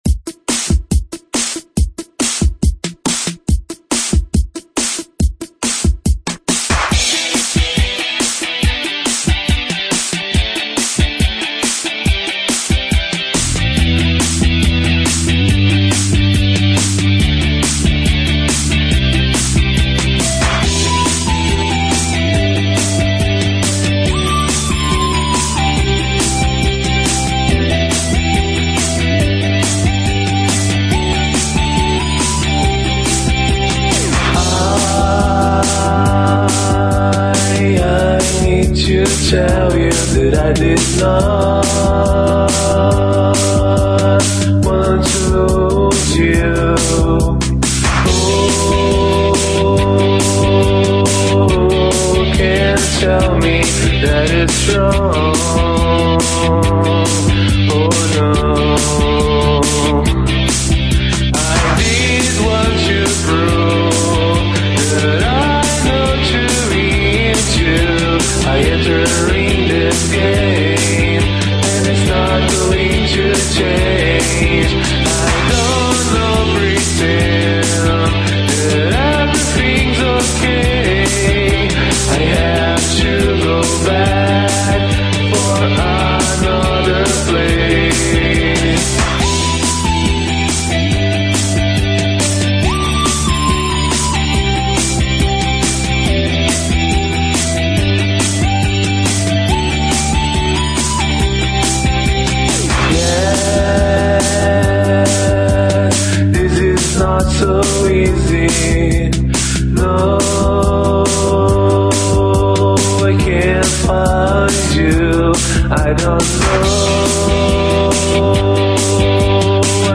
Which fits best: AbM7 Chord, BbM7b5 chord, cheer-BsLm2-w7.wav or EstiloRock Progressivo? EstiloRock Progressivo